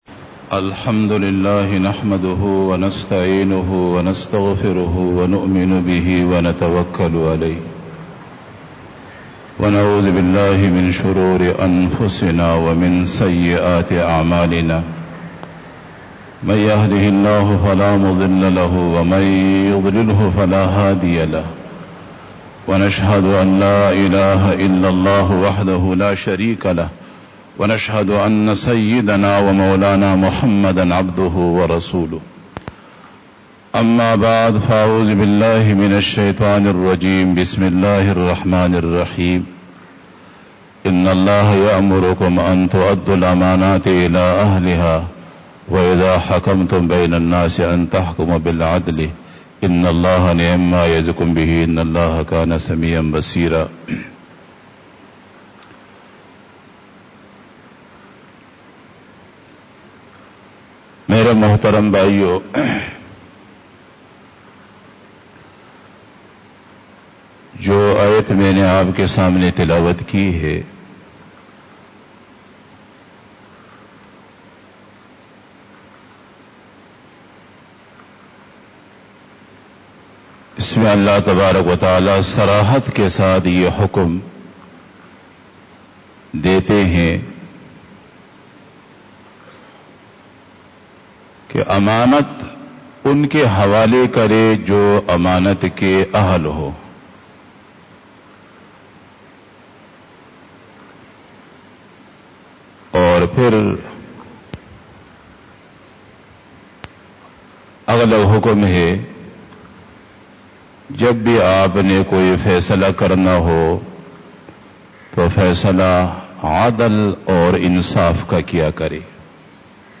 بیان جمعۃ المبارک
02:17 PM 658 Khitab-e-Jummah 2021 --